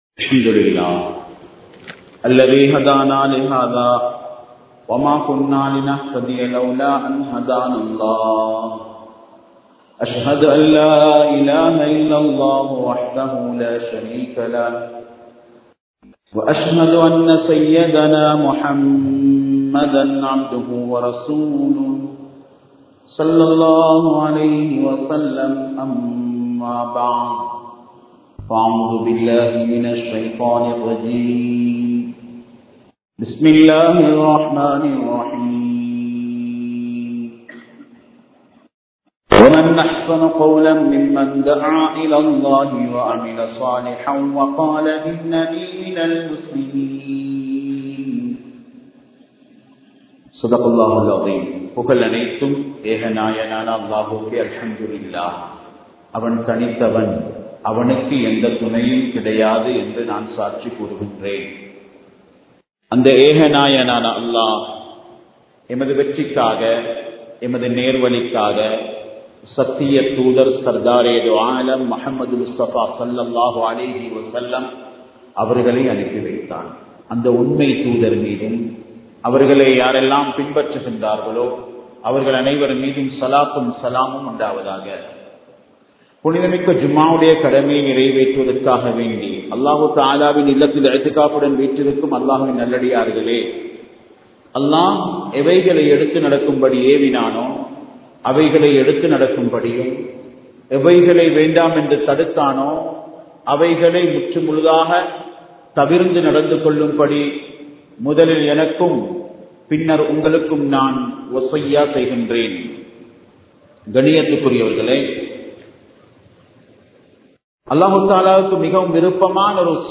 உண்மையான முஸ்லிமின் தன்மைகள் | Audio Bayans | All Ceylon Muslim Youth Community | Addalaichenai
Kandy, Kattukela Jumua Masjith